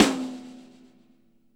rolli ring snare.wav